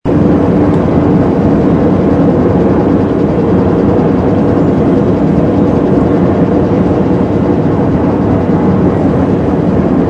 ambience_shipbuy.wav